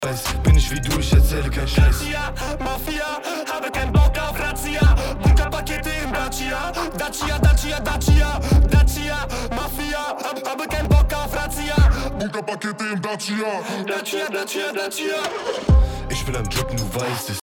Mit Tuning
autotune__at_auf_rap_main__backup_wet.mp3